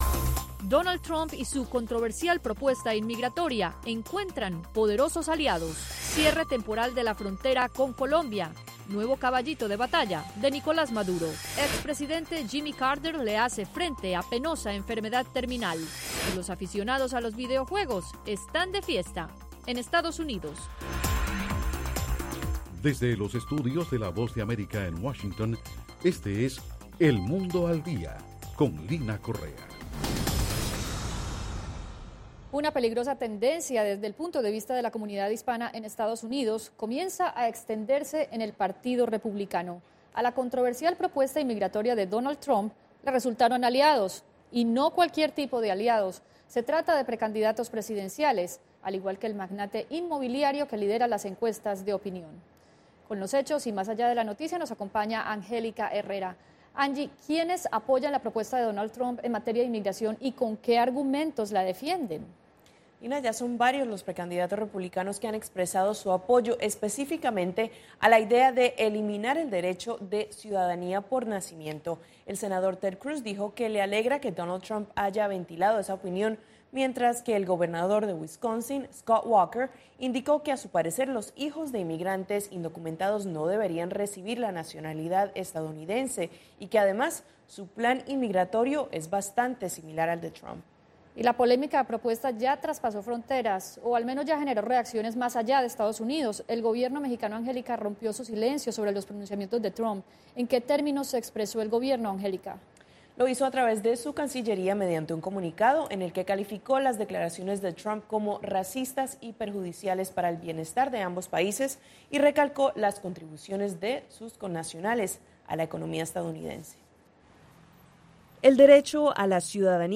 Una transmisión simultanea del noticiero de televisión “El mundo al día” en radio.